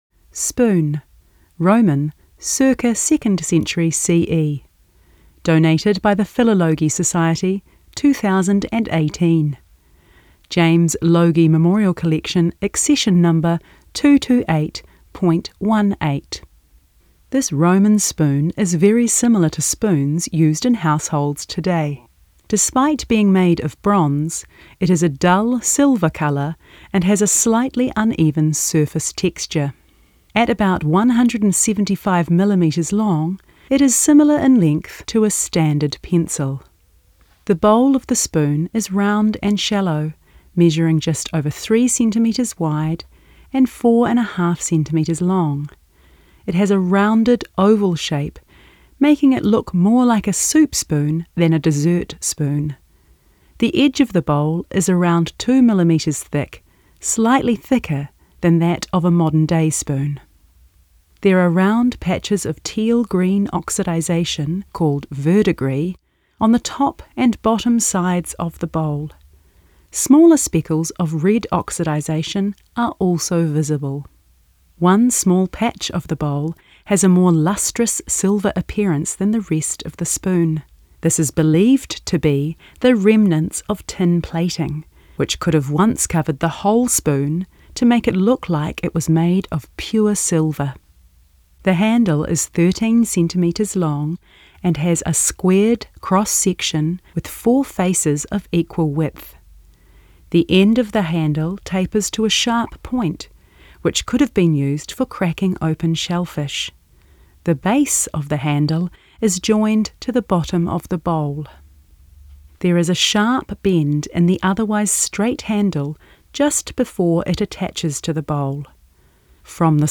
Audio Descriptions – Teece Museum of Classical Antiquities